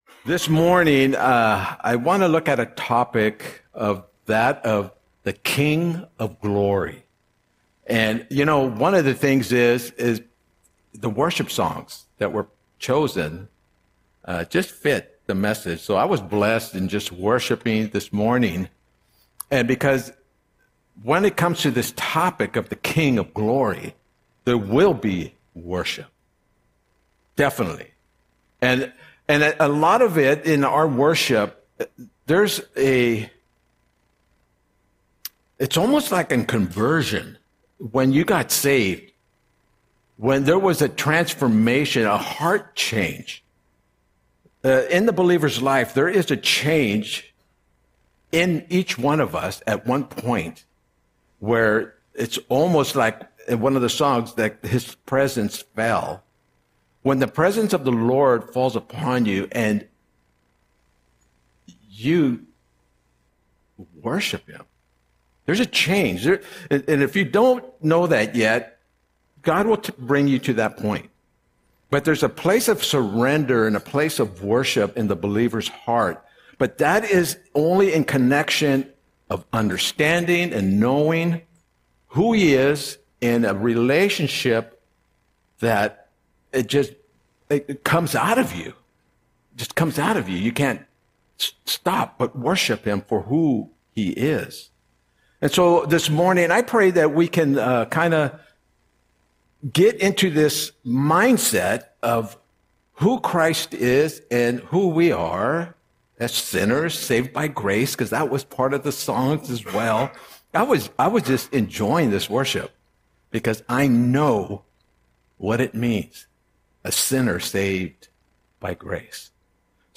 Audio Sermon - January 11, 2026